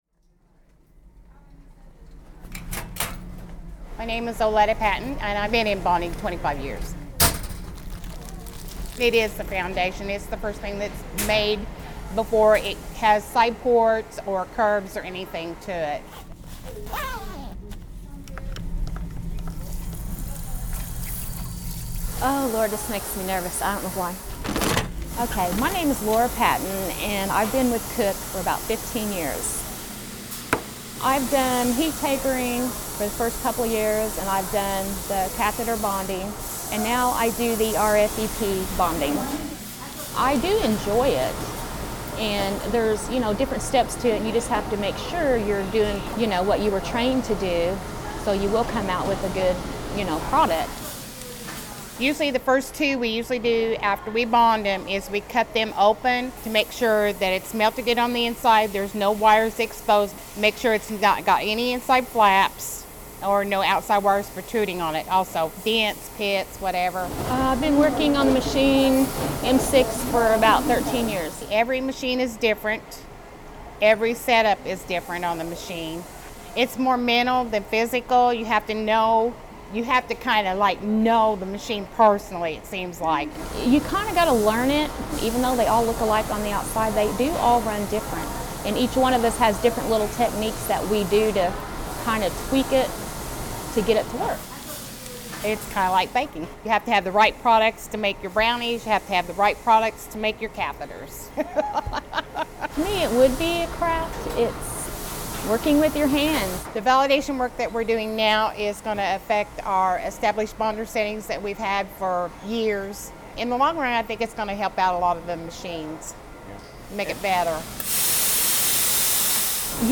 We caught up with a couple members of the bonding team to hear their thoughts about their work and how things are changing through the validation process.